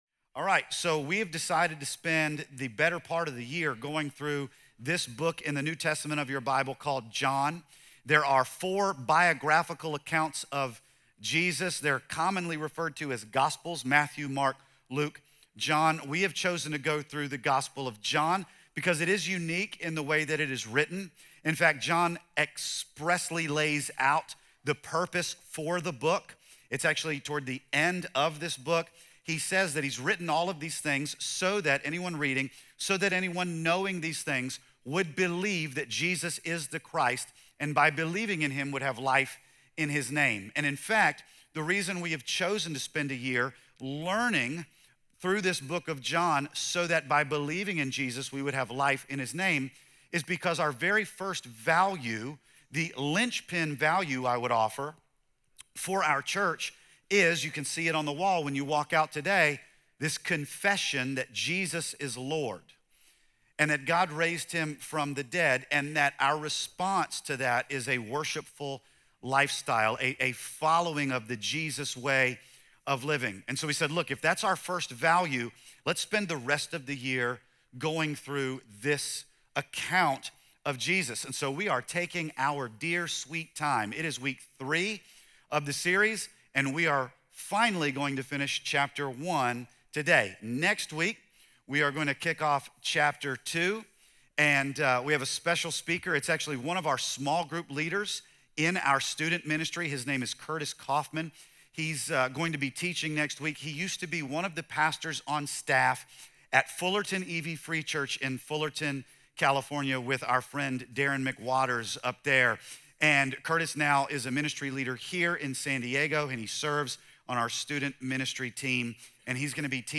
John 1:29-50 - Sonrise Church, Santee